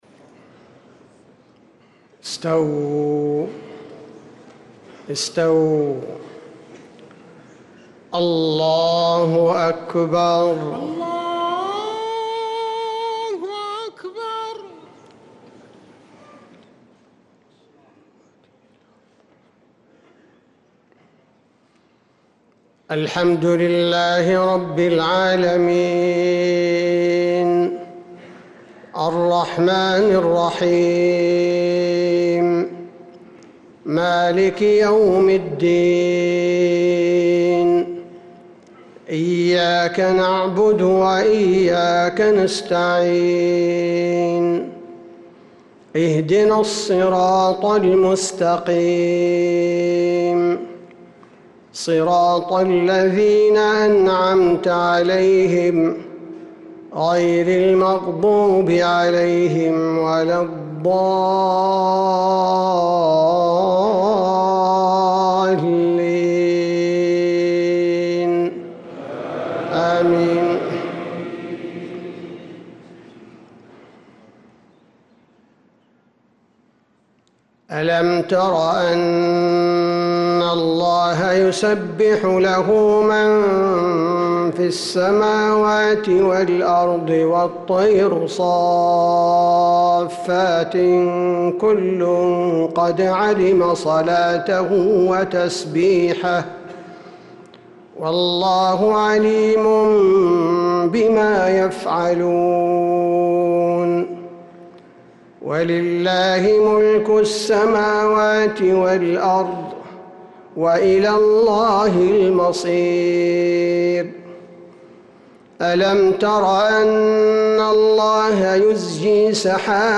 صلاة المغرب للقارئ عبدالباري الثبيتي 15 رجب 1446 هـ
تِلَاوَات الْحَرَمَيْن .